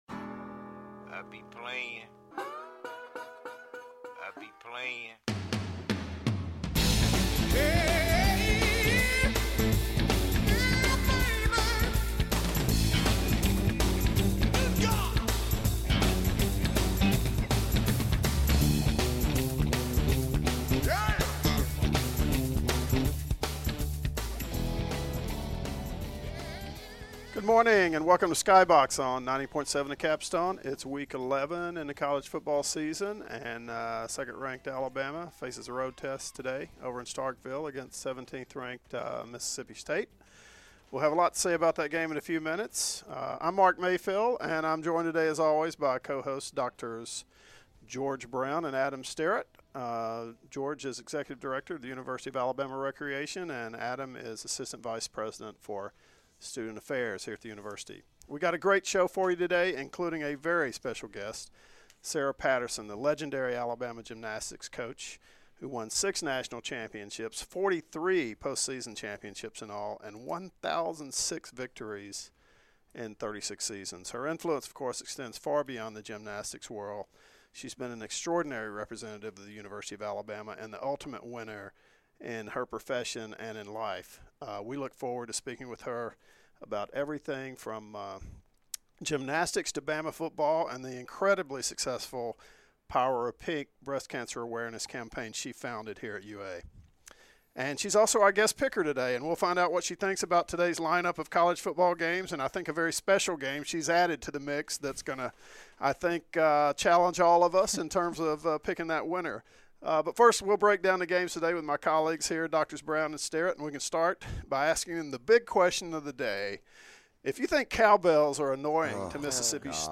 Skybox is WVUA's sports entertainment show hosted by UA professionals